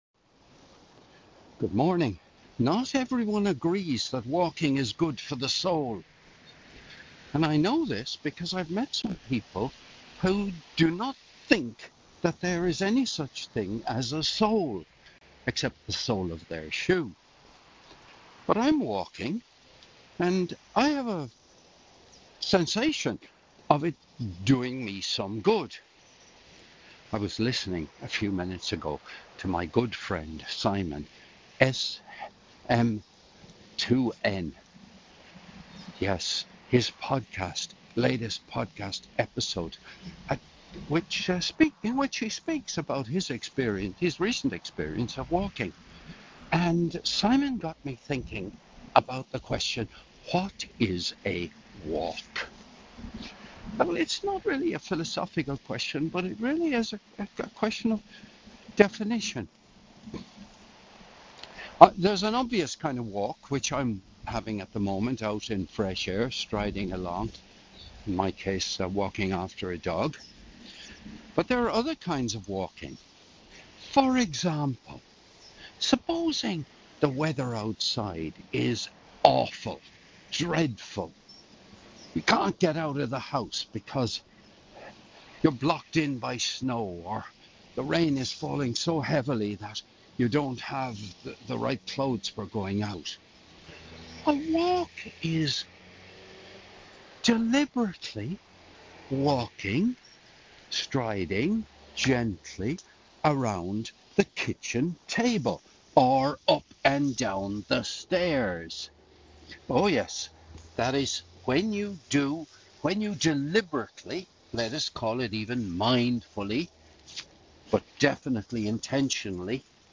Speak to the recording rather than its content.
while walking